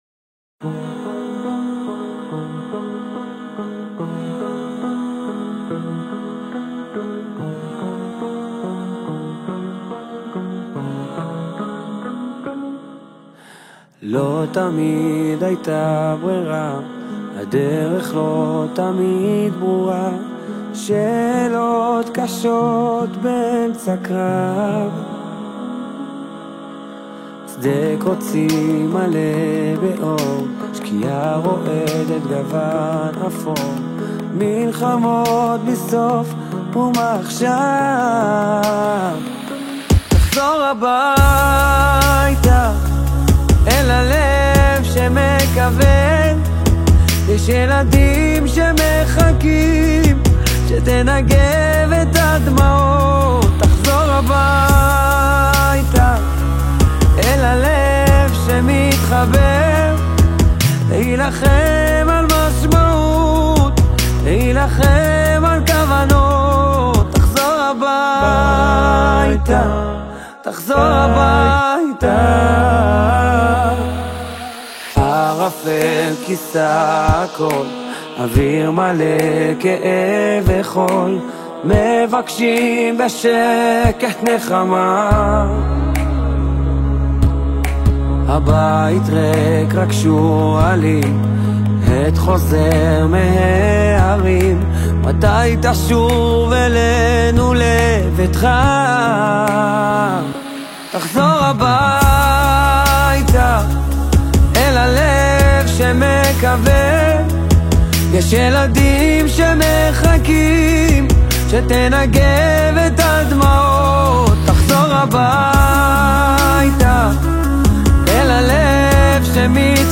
בז'אנר הים תיכוני